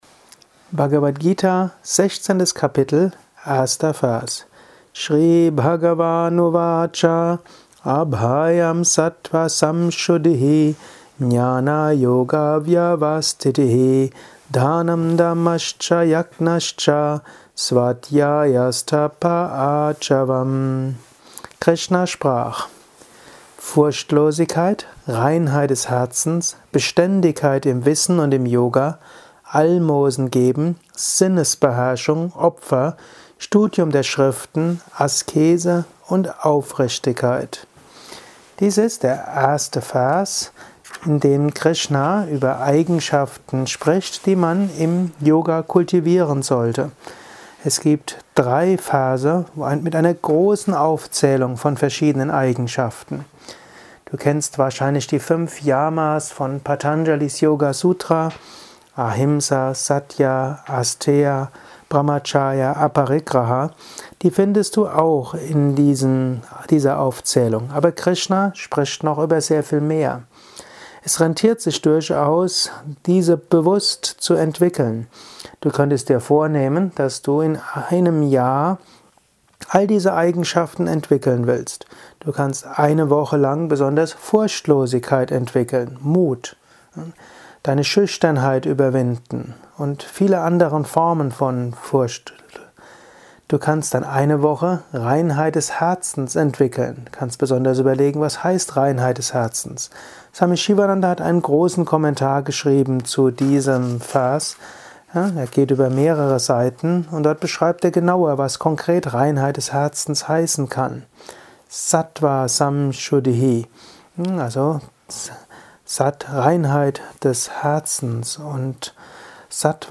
Dies ist ein kurzer Kommentar als Inspiration für den heutigen